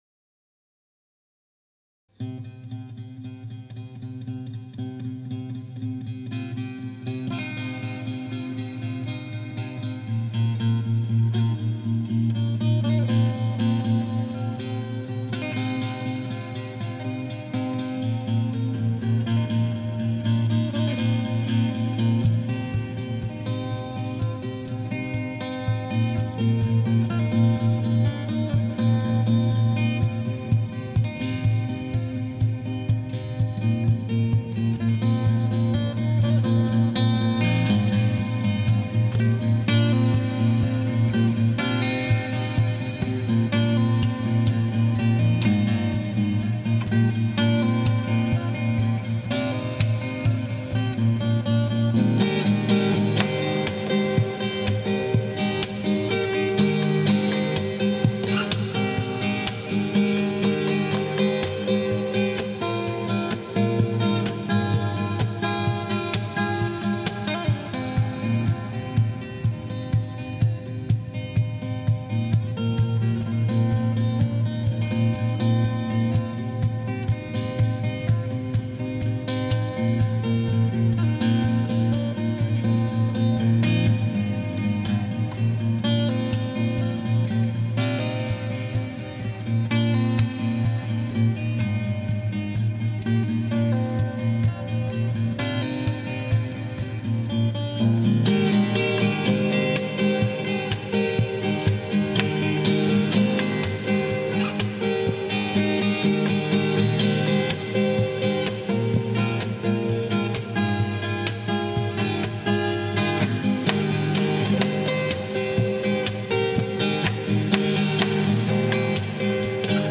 Fingerstyle arrangement edition